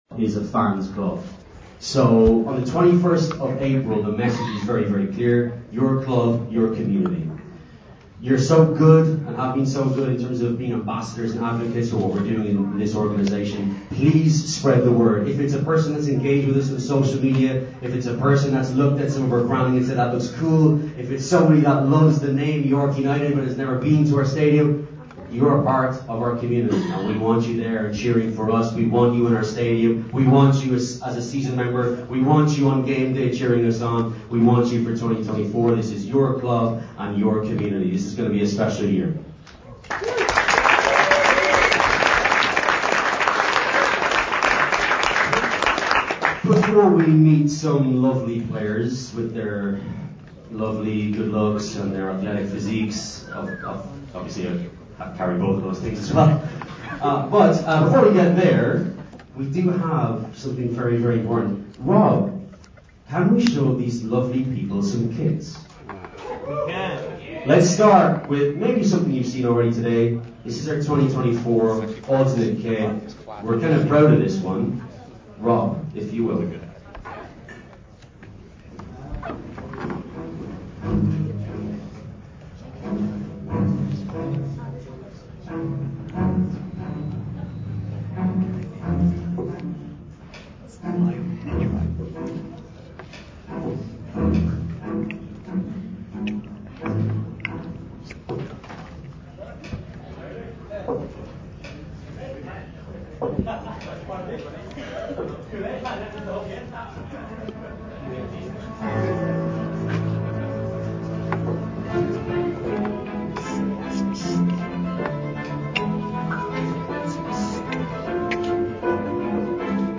2024...York United FC 2024 Kit Reveal Party